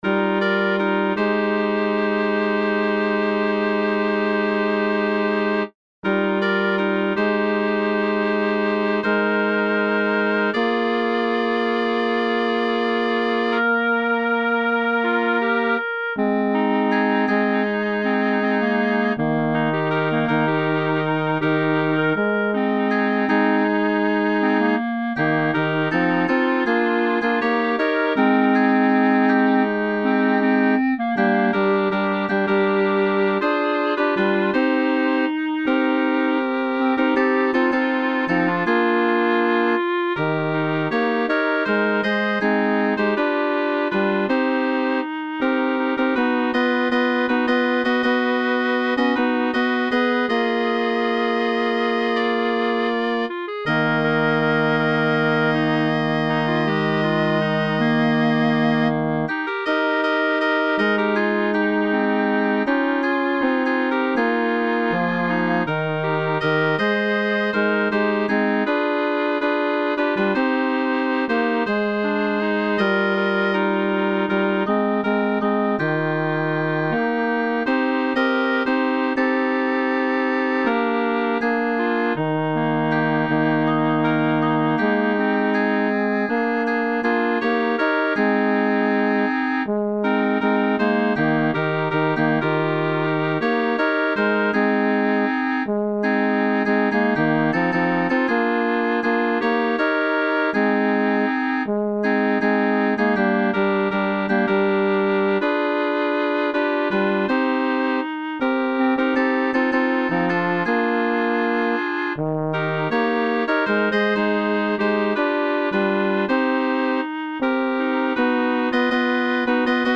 Performance begins at 3:49
Midi Preview